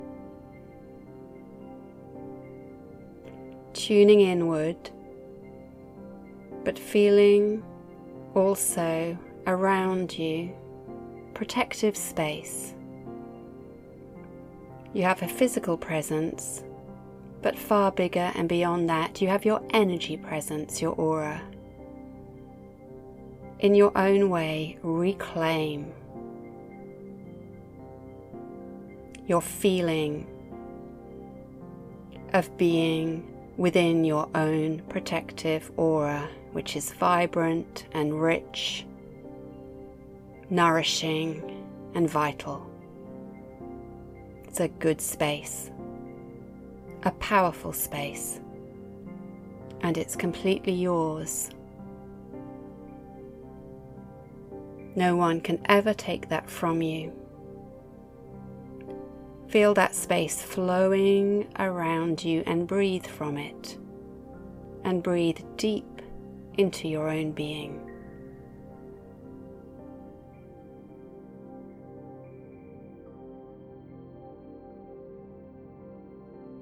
This  meditation uses a tree visualisation to feel and reclaim your inner strength and power, to stand your ground, to be in your own strong presence and to feel your roots.